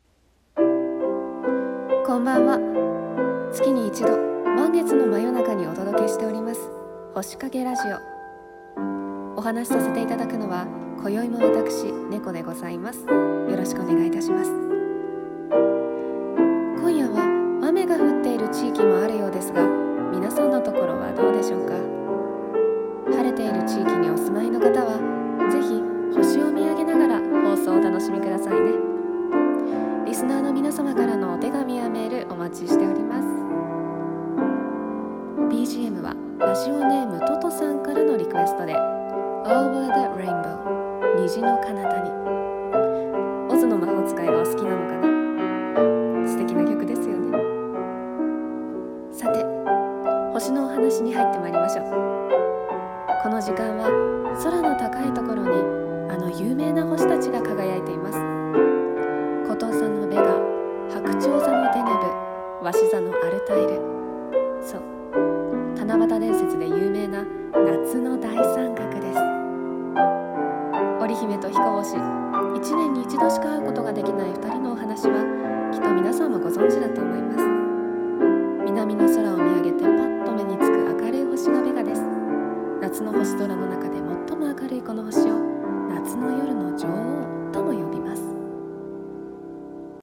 朗読台本「星影ラジオ 夏」